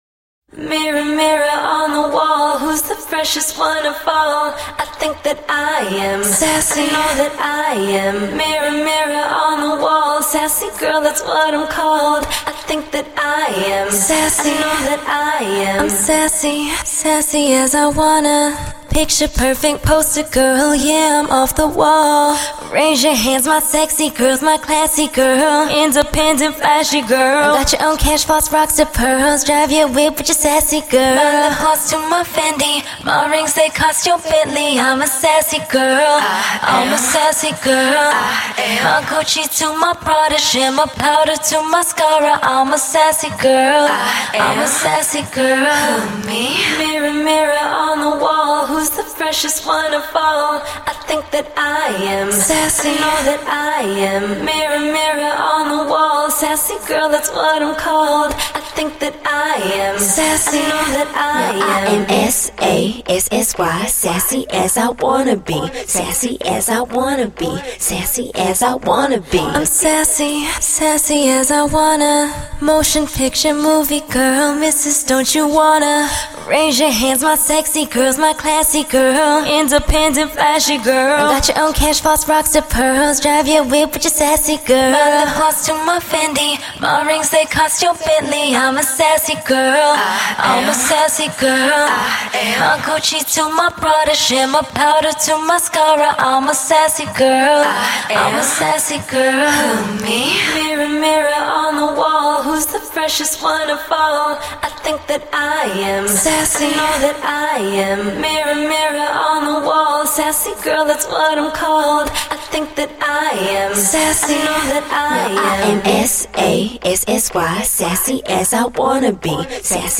Скачать Зарубежные акапеллы [150]